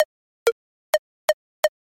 脉冲式小鼓滚动
标签： 128 bpm House Loops Drum Loops 324.03 KB wav Key : Unknown
声道立体声